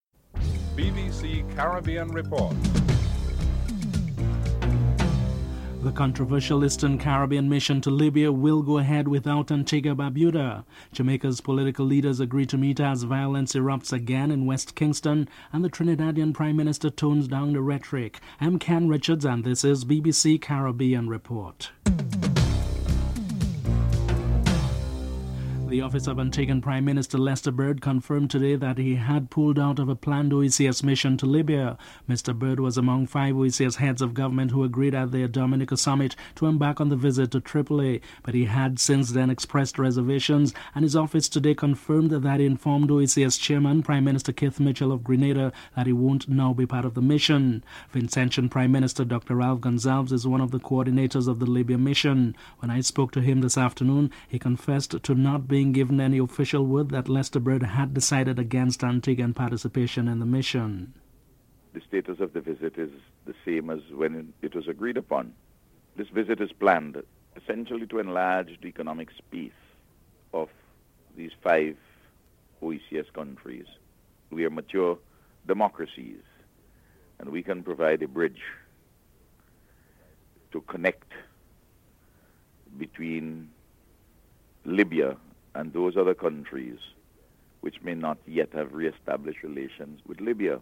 1. Headlines (00:00-00:24)
2. Controversial list and Caribbean mission to Libya will go ahead without Antigua and Barbuda. Prime Minister Ralph Gonsalves and Dominica's Opposition Leader Edison James are interviewed (00:25-04:42)